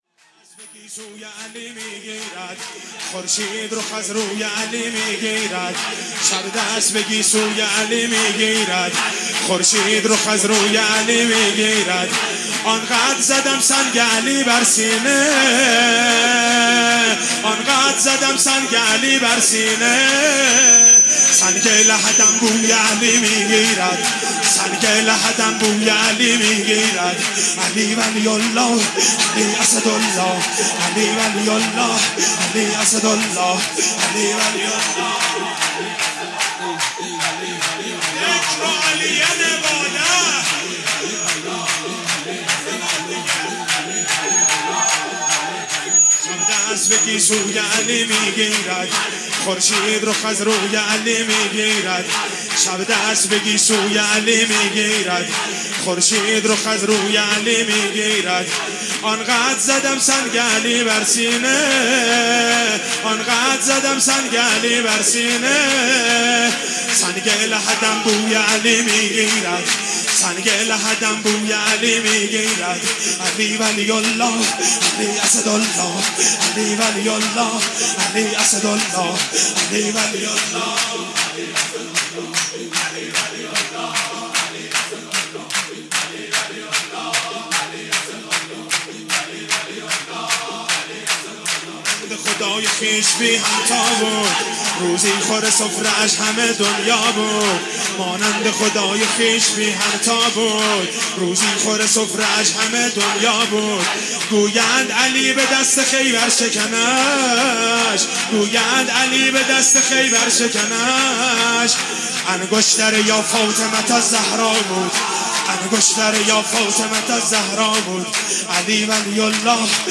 ولادت حضرت علی علیه السلام
سرود مولودی